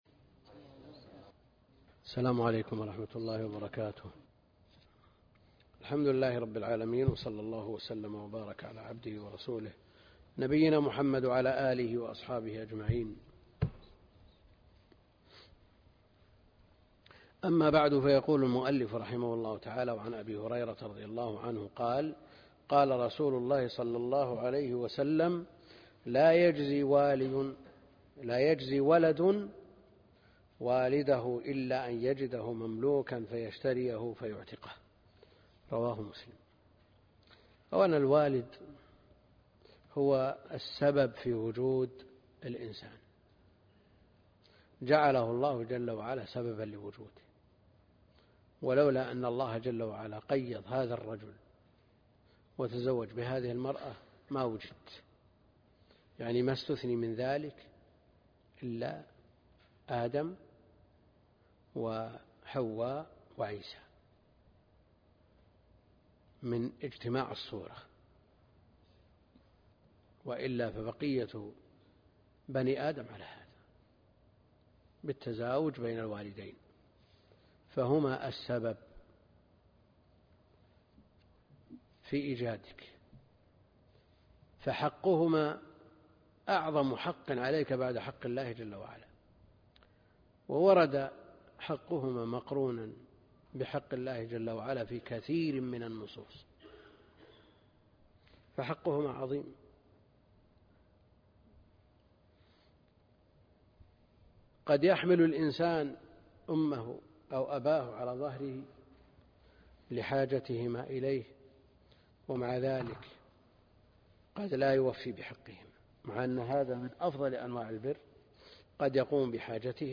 الدرس (2) كتاب العتق من بلوغ المرام - الدكتور عبد الكريم الخضير